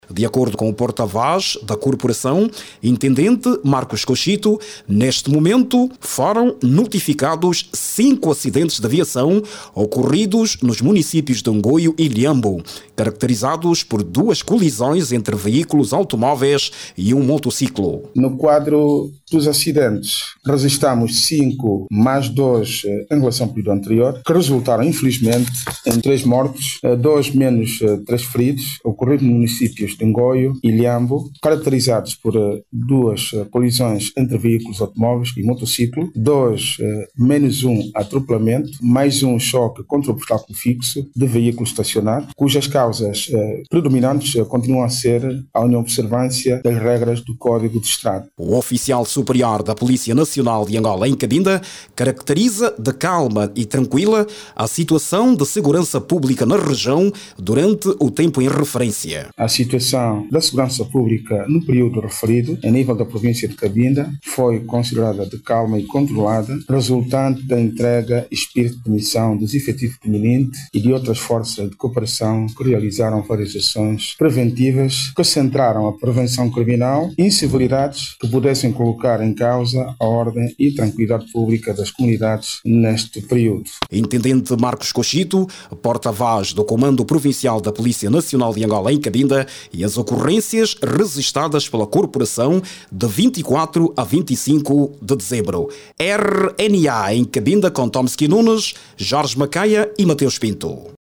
Acidentes de viação nas últimas 72 horas na província de Cabinda causaram três mortos segundo os dados avançados pela Polícia Nacional no quadro da Operação Sentinela desencadeada neste período festivo. Entretanto, a polícia em Cabinda, garante ser calma a situação de segurança durante esta quadra festiva. Saiba mais dados no áudio abaixo com o repórter